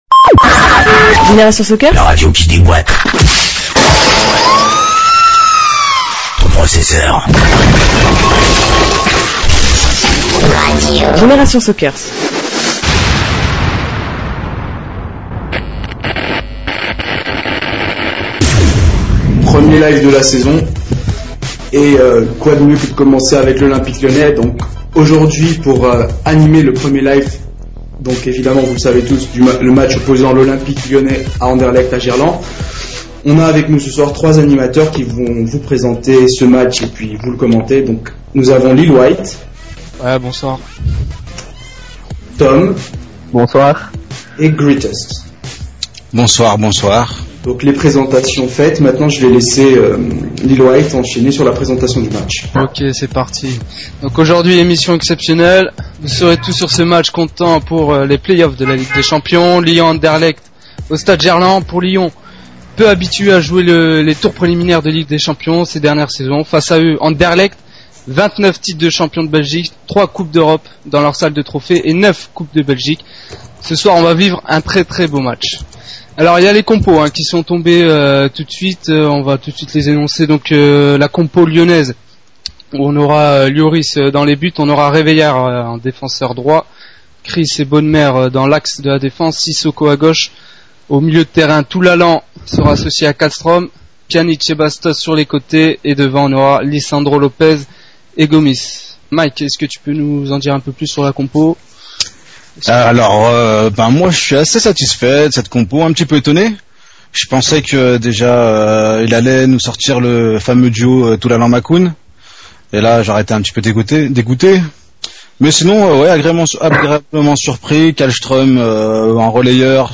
Live sur Génération Soccers
Un match commenté par